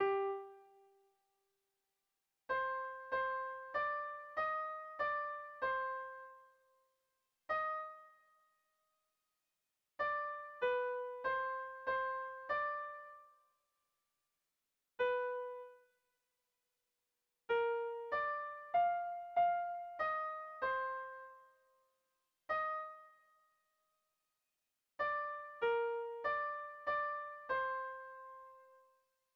Gabonetakoa
AB